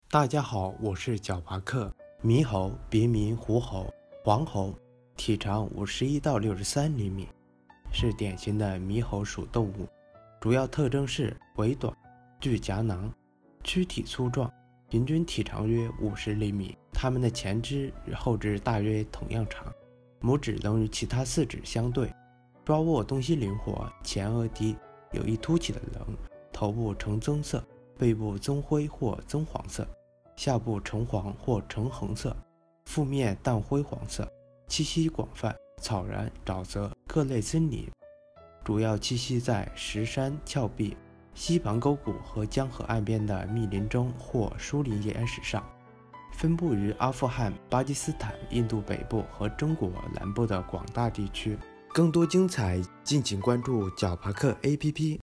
猕猴-----呼呼呼～～～